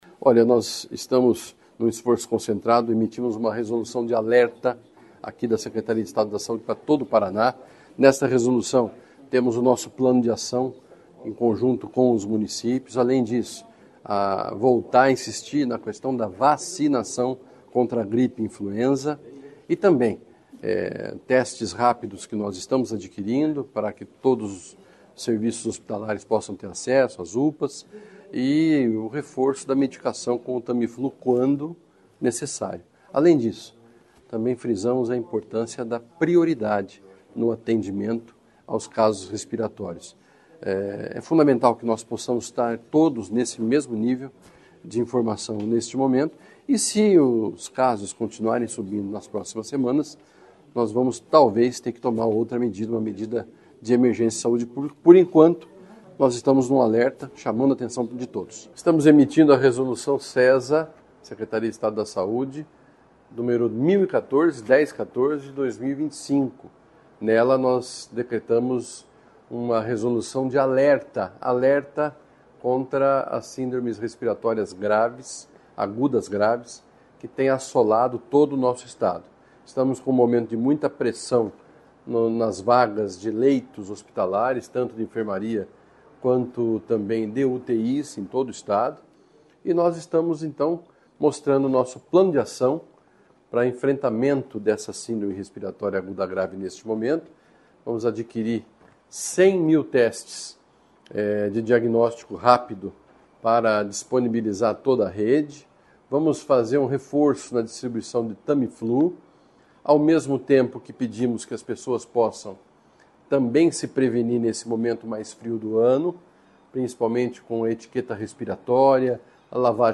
Sonora do secretário Estadual da Saúde, Beto Preto, sobre o estado de alerta estadual em relação aos vírus respiratórios